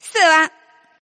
Index of /client/common_mahjong_tianjin/mahjonghntj/update/1308/res/sfx/tianjin/woman/